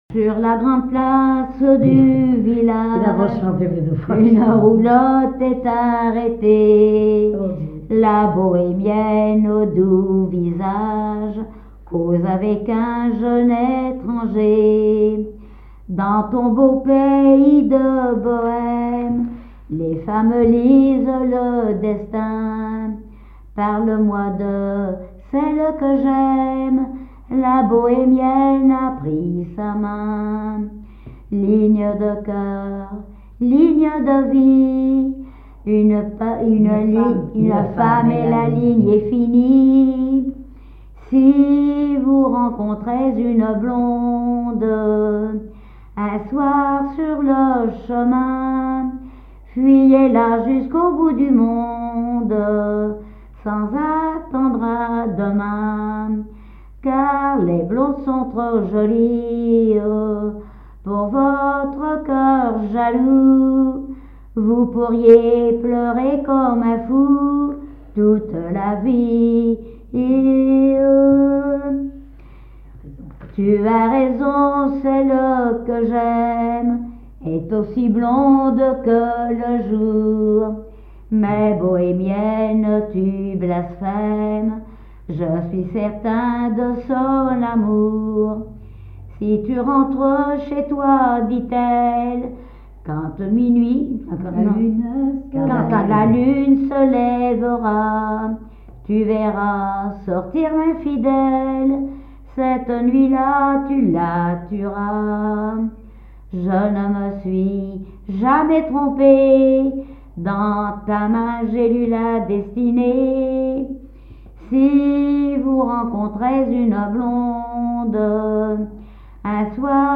Genre strophique
témoignages sur le poissonnerie et chansons
Pièce musicale inédite